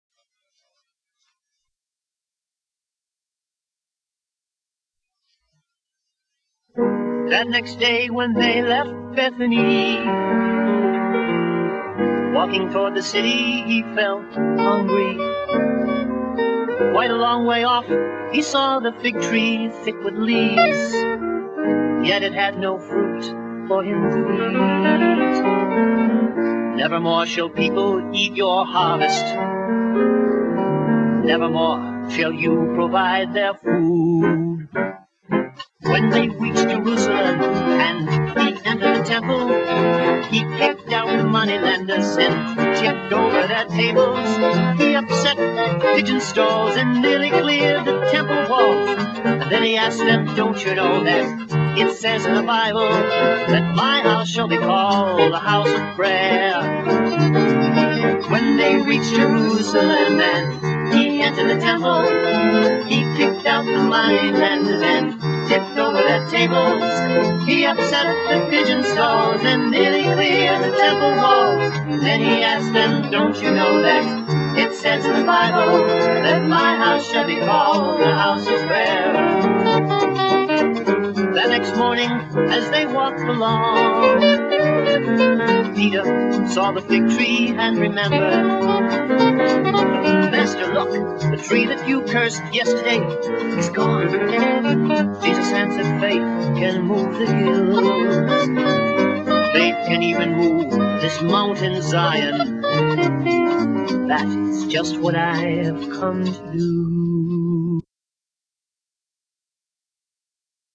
VOCALISTS
ORGAN, SYNTHESIZER
GUITAR, SYNTHESIZER
BASS GUITAR
DRUMS